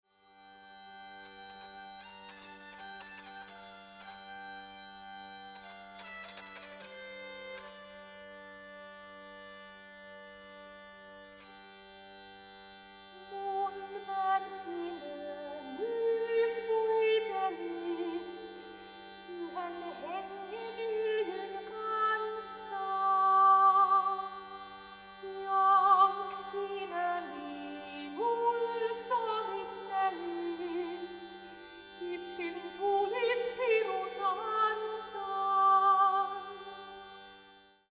sopraano, dulcimer, sinfonia ja 5-kielinen kantele